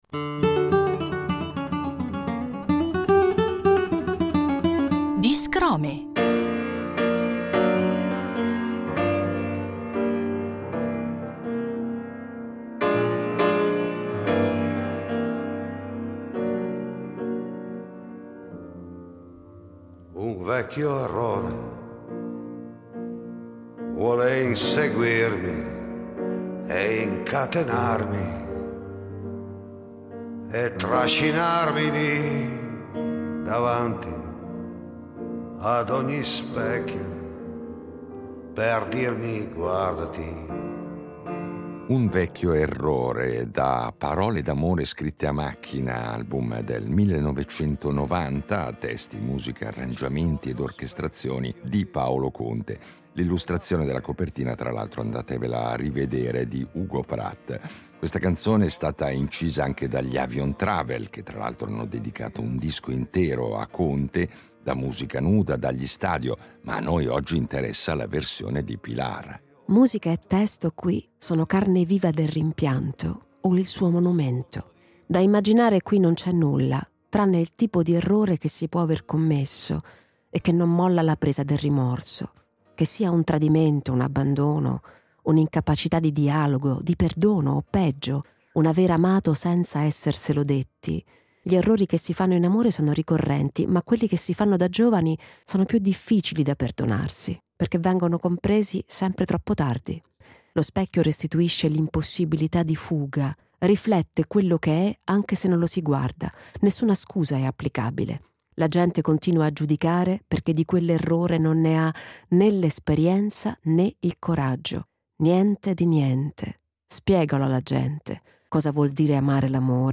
In questa serie di Biscrome ci presenta con parole sue le molte canzoni che ci ha regalato per programmi diversi, oltre al suo recente progetto discografico dal titolo eloquente, Canto Conte, che l’artista descrive non solo come una sfida interpretativa, ma come un vero e proprio «bisogno», «una necessità intima personale, oltre che artistica».